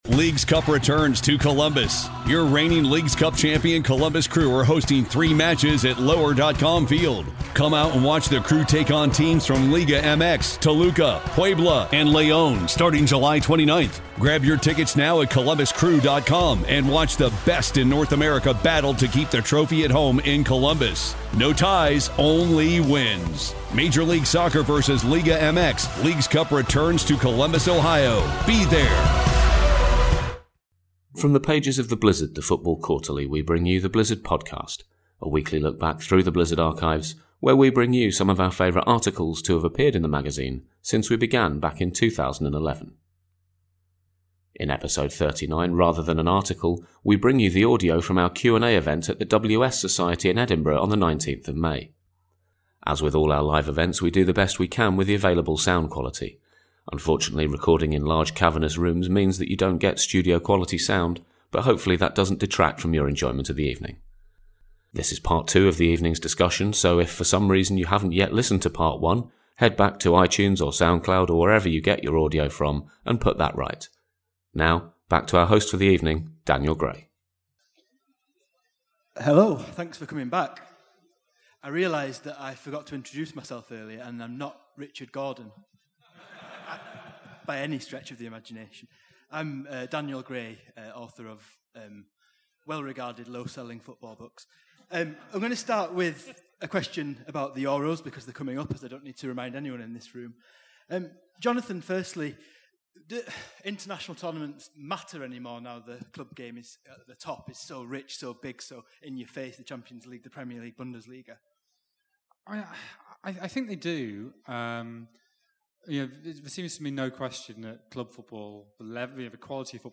The second half of the Blizzard's live Q&A event held at the WS Society in Edinburgh on 19th May.